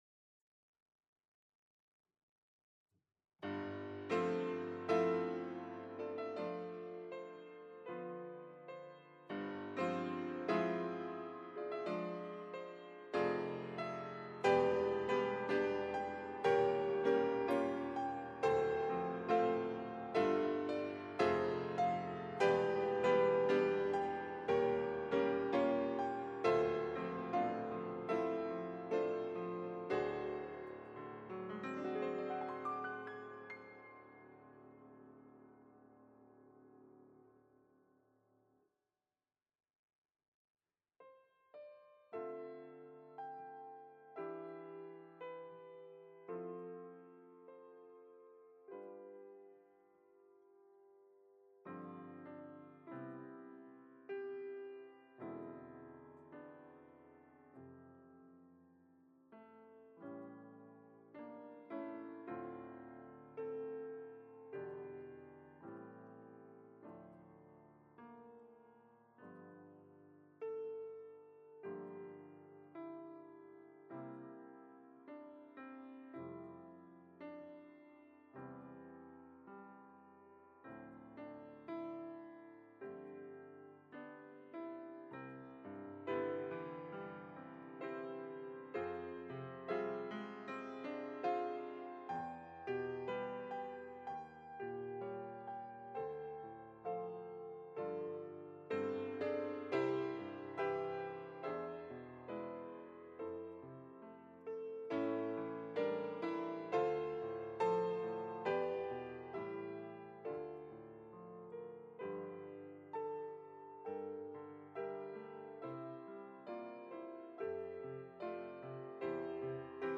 특송과 특주 - 참 아름다워라